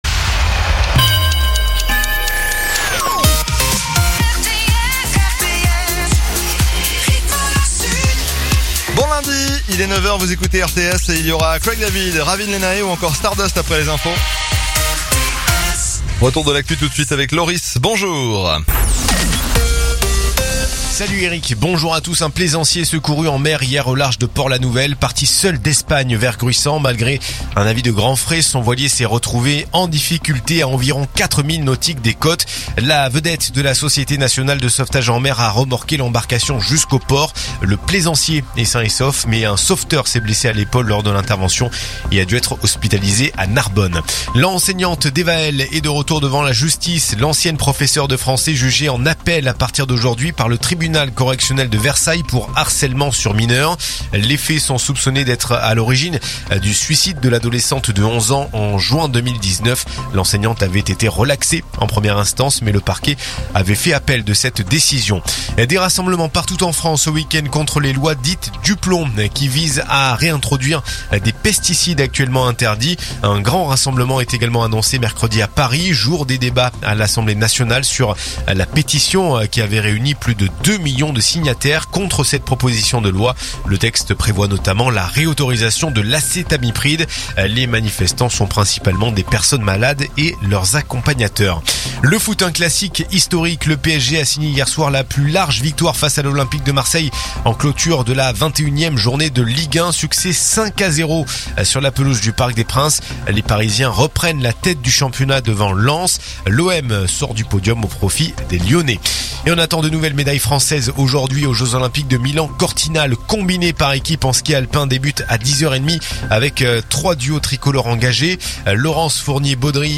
RTS : Réécoutez les flash infos et les différentes chroniques de votre radio⬦
info_narbonne_toulouse_654.mp3